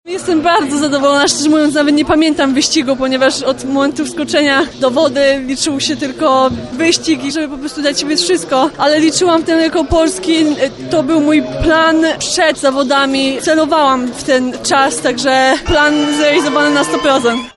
Mówi Katarzyna Wasick, nowa rekordzistka Polski.